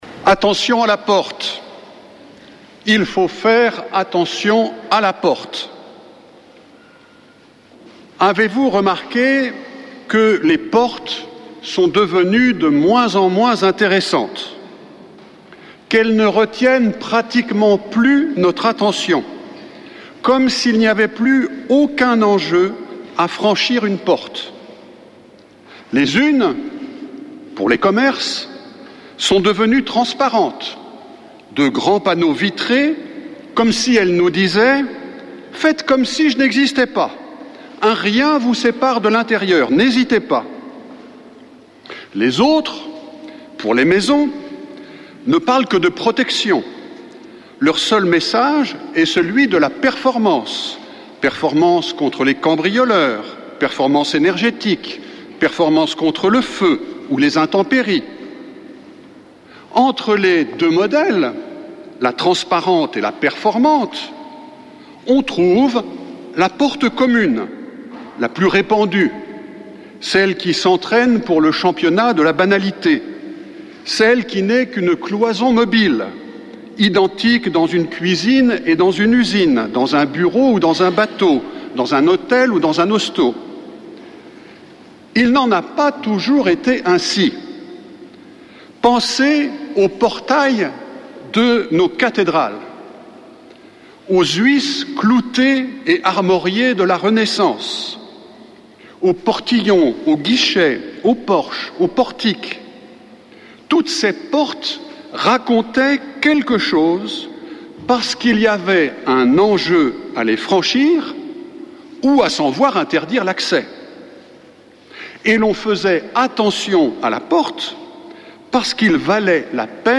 Messe depuis le couvent des Dominicains de Toulouse